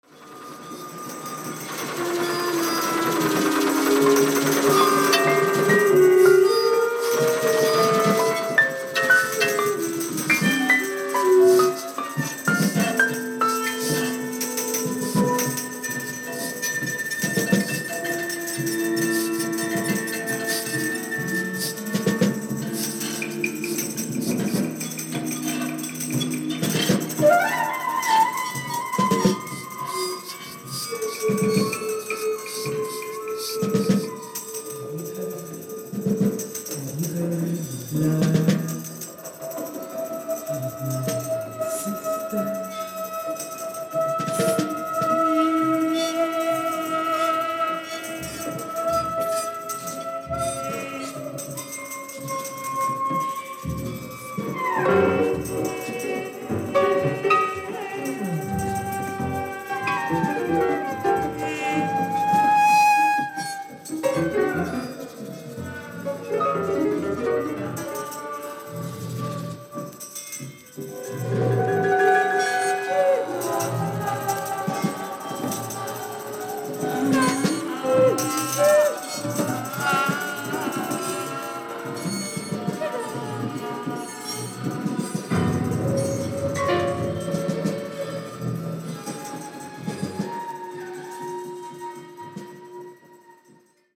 Piano, Bells, Gong, Percussion
Saxophone, Clarinet, Flute